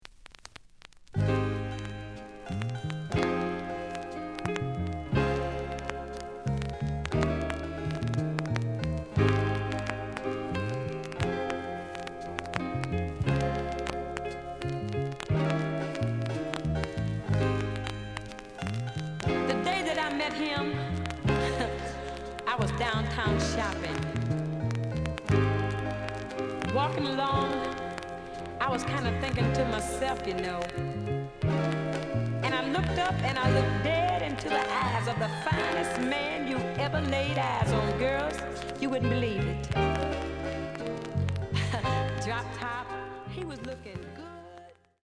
The listen sample is recorded from the actual item.
●Genre: Funk, 70's Funk
Some noise on B side.